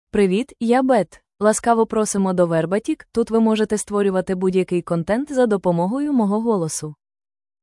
Beth — Female Ukrainian AI voice
Beth is a female AI voice for Ukrainian (Ukraine).
Voice sample
Listen to Beth's female Ukrainian voice.
Female
Beth delivers clear pronunciation with authentic Ukraine Ukrainian intonation, making your content sound professionally produced.